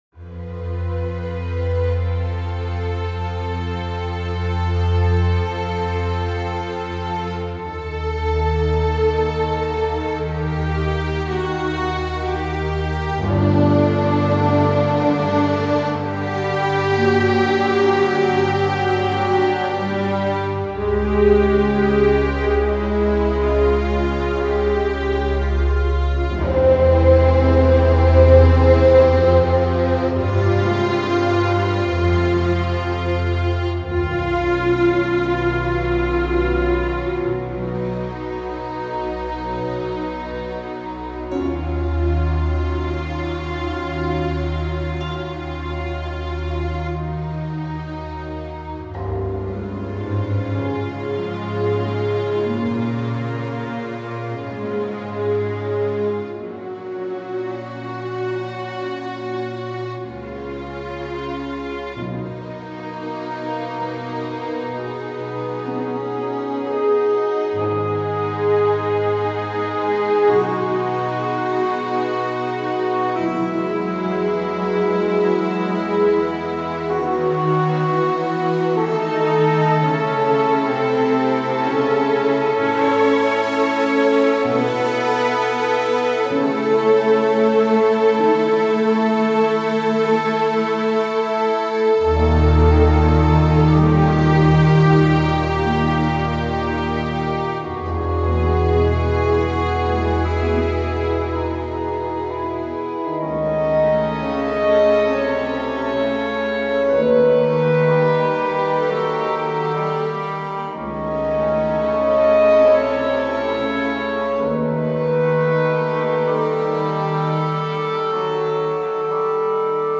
re-recorded
and sounding bittersweet and lovely.